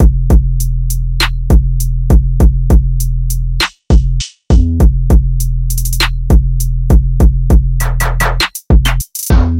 描述：鼓，鼓循环，鼓循环，房子，房子循环
Tag: 125 bpm House Loops Drum Loops 662.05 KB wav Key : Unknown